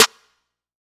MZ Snareclap [Metro #9].wav